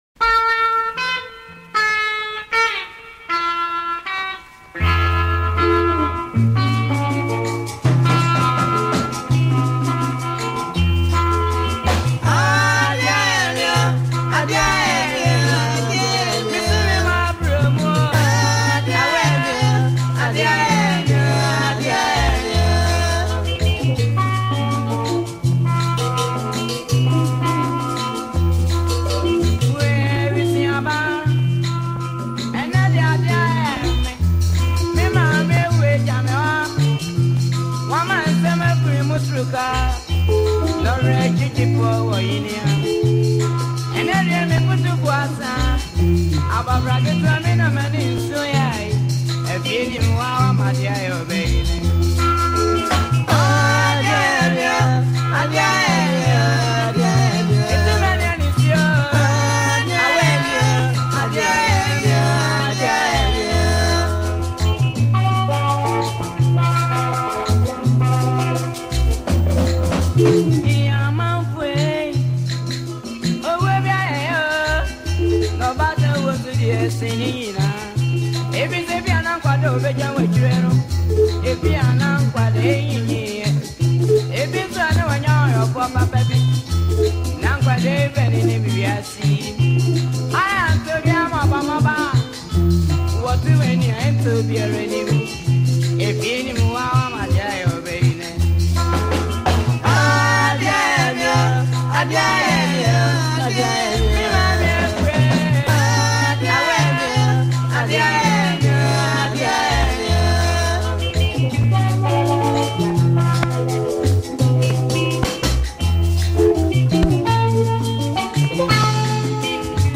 an old highlife song